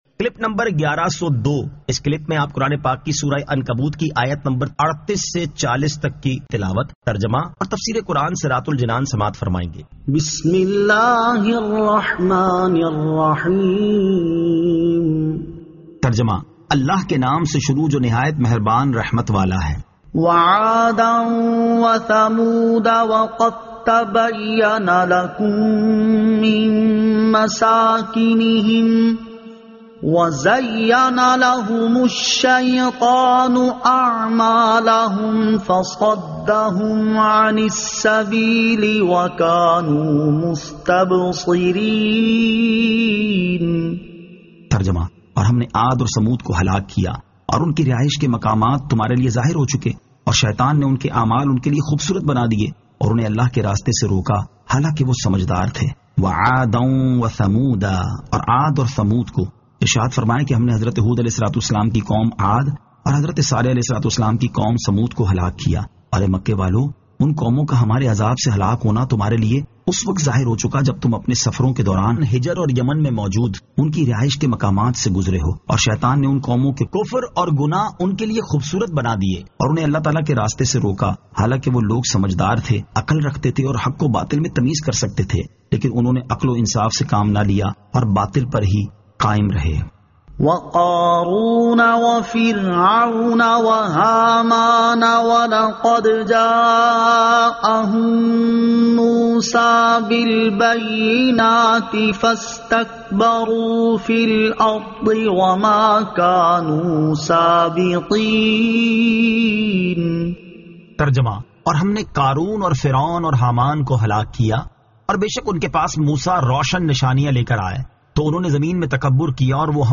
Surah Al-Ankabut 38 To 40 Tilawat , Tarjama , Tafseer